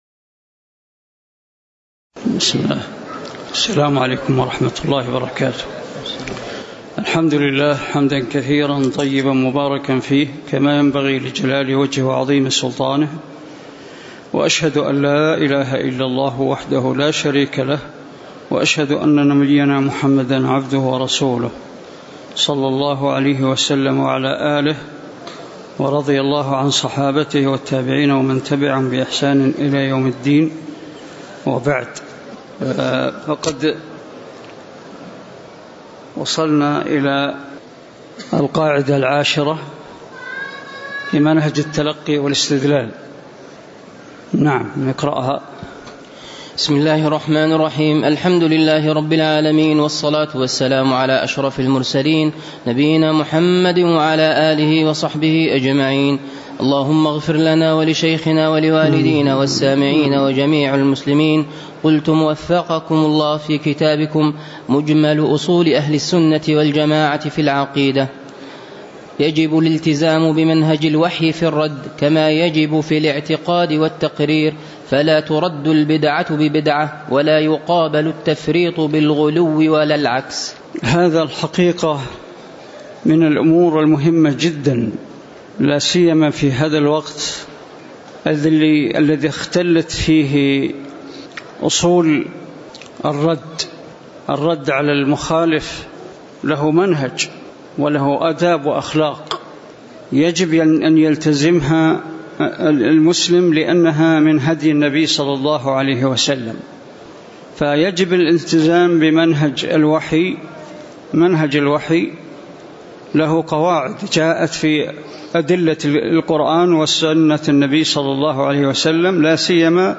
تاريخ النشر ٢٨ ربيع الثاني ١٤٣٩ هـ المكان: المسجد النبوي الشيخ: ناصر العقل ناصر العقل القاعدة العاشرة بمنهج التلقي والاستدلال (003)